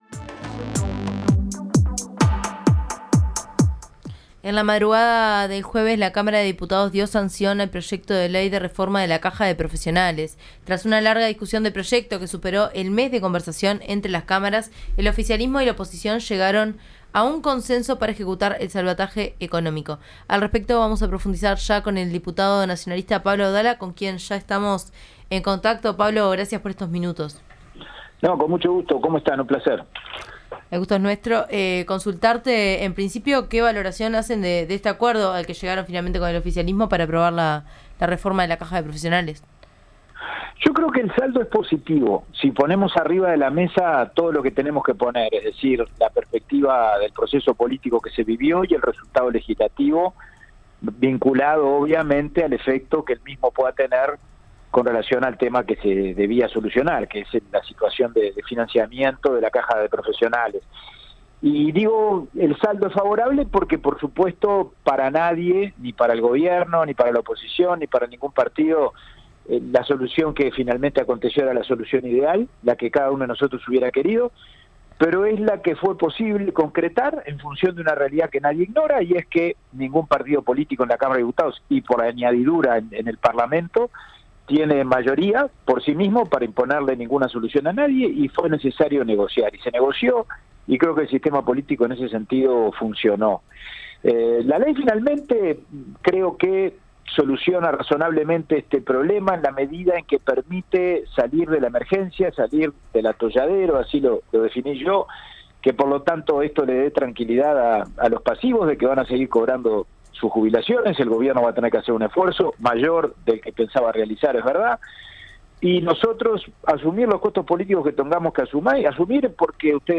El miércoles, la Cámara de Senadores aprobó el proyecto de reforma de la Caja de Jubilaciones y Pensiones de Profesionales Universitarios, y el jueves la Cámara de Diputados le dio sanción completa para que sea remitido al Poder Ejecutivo. Sobre las negociaciones, lo que implica este proyecto y el rumbo del gobierno, conversamos con el senador del Frente Amplio y coordinador de la bancada, Daniel Caggiani.